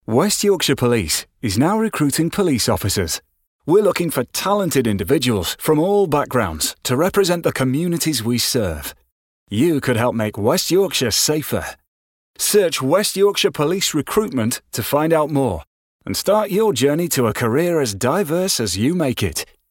Positive Action - Radio Advert